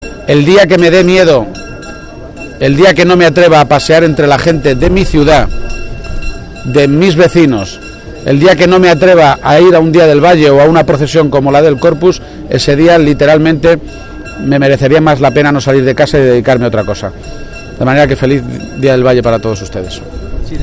García-Page, que realizó estas declaraciones durante la tradicional Romería del Valle de Toledo, volvió a reiterar el compromiso adoptado ayer en un acto público en Bolaños de Calatrava, de que si es elegido presidente de Castilla-La Mancha su continuidad en la política quedaría condicionada a “darle la vuelta drásticamente a las cifras del paro y a esa servidumbre laboral que están sufriendo miles de trabajadores, que hoy cobran la mitad y trabajan más de lo que hacían antes”.
Cortes de audio de la rueda de prensa